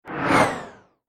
جلوه های صوتی
دانلود صدای باد 66 از ساعد نیوز با لینک مستقیم و کیفیت بالا